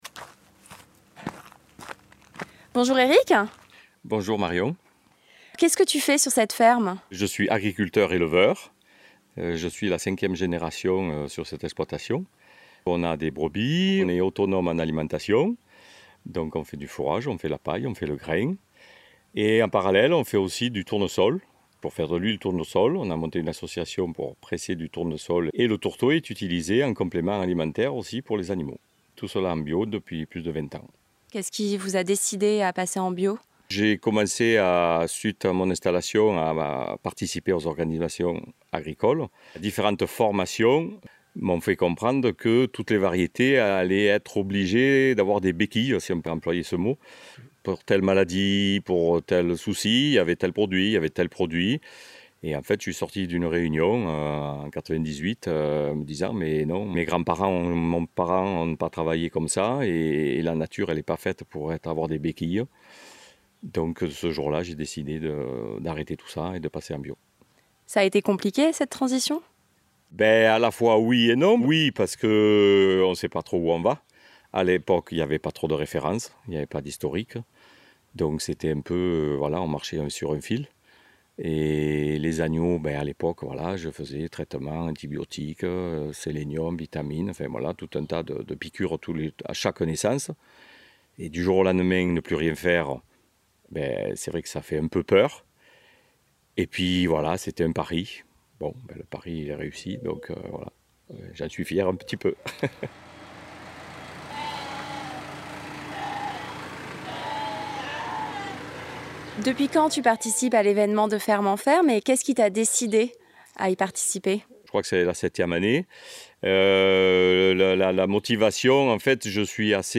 Partez désormais à la rencontre d’un éleveur